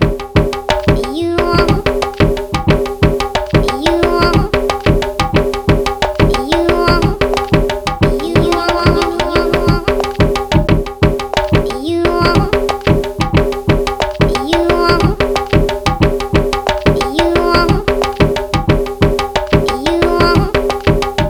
Show You How To Hustle (Indian Loop).wav